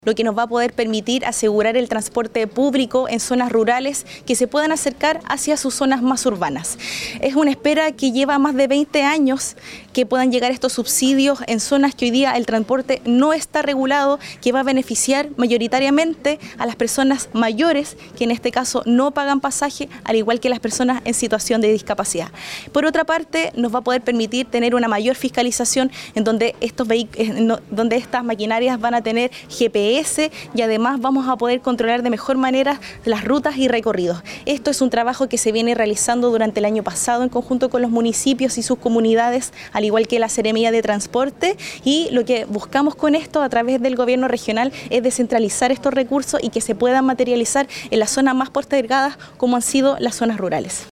La Presidenta de la Comisión de Transporte del CORE, Giselle Ahumada, dijo que el beneficio queda garantizado por los próximos 3 años.